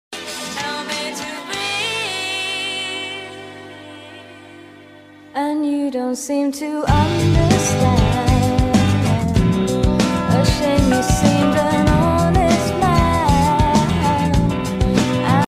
KA Purwojaya menyusul KA Progo sound effects free download
KA Purwojaya menyusul KA Progo distasiun Haurgeulis